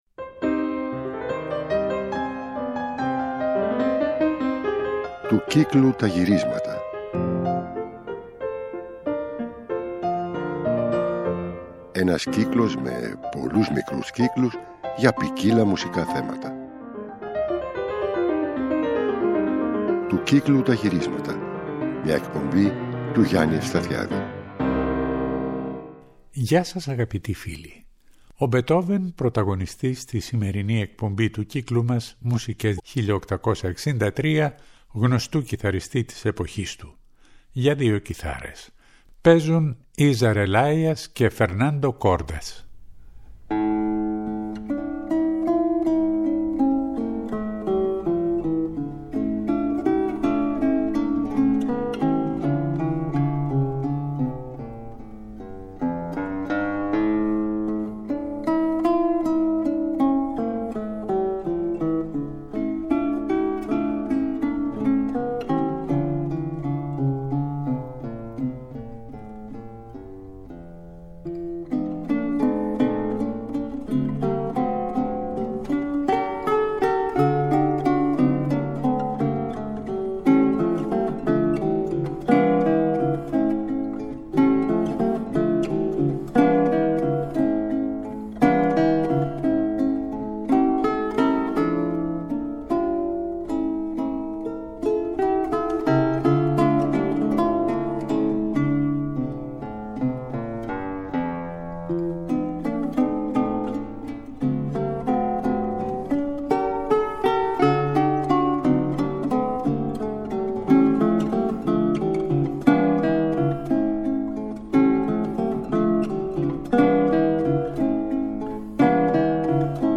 Ακολουθεί μεταγραφή της 9ης συμφωνίας για βιολί και πιάνο (με χαρακτηριστικά αποσπάσματα και από τα 4 μέρη) και τέλος η κορύφωση του αυστριακού Wolfgang Mitterer (γεν. 1958) ο οποίος διασκευάζει και ανασυνθέτει τις 9 συμφωνίες σε 1 συνενώνοντας χαρακτηριστικά μέρη από όλες!